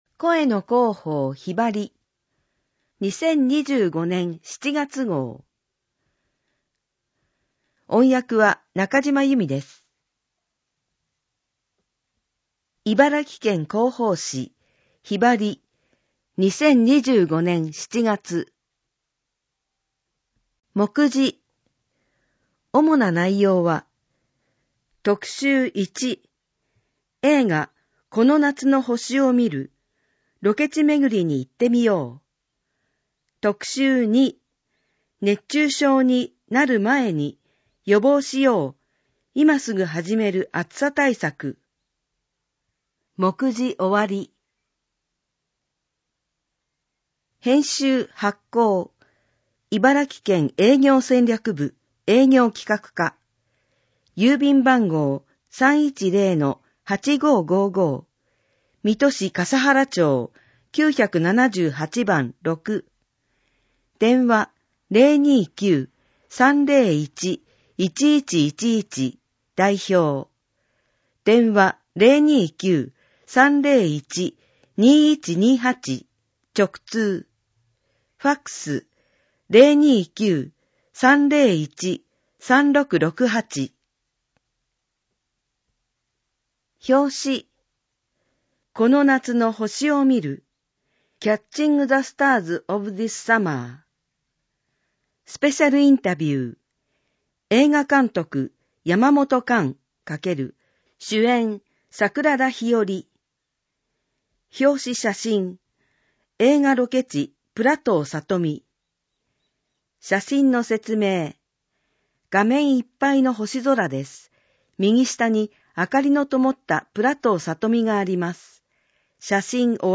【特集2】熱中症に「なる前に」今すぐ始める暑さ対策 【お知らせひろば】 県からのお知らせ 【催し物ガイド】 県内の美術館・博物館などの企画展をご紹介 【その他トピックス】知事コラム、クロスワードパズルなど 知事コラム、クロスワードパズルなど 音声版・点字版・電子版県広報紙「ひばり」 音声版 視覚障害の方を対象に音声版も発行しています。 声の広報「ひばり」 音声を再生するためには、 「QuicktimePlayer」（外部サイトへリンク） 、 「WindowsMediaPlayer」（外部サイトへリンク） 、 「RealPlayer」（外部サイトへリンク） （いずれも無料）などが必要です。